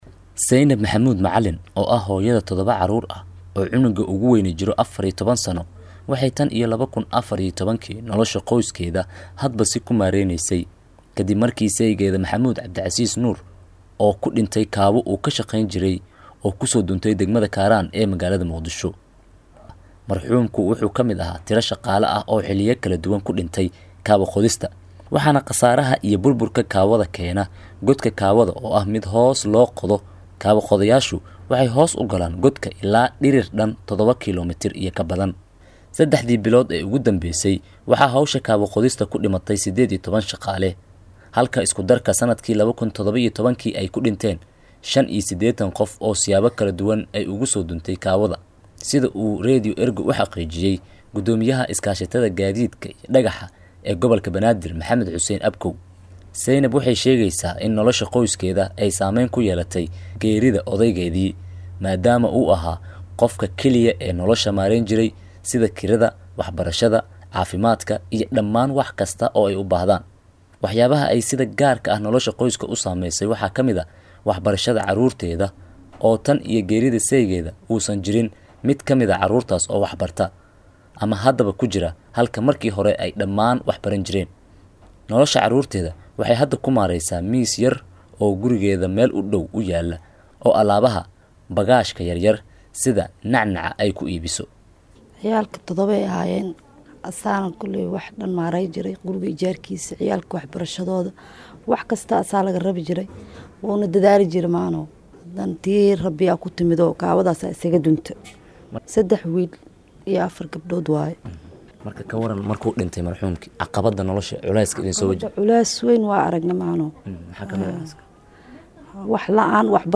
Warbixin-qoysas-noloshooda-ay-ku-tiirsanayd-ragooda-oo-kaawada-ka-shaqeeya-2.mp3